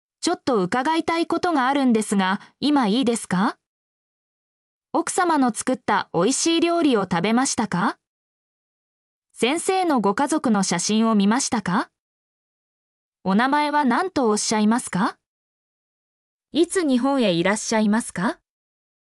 mp3-output-ttsfreedotcom-15_NH9FbwGK.mp3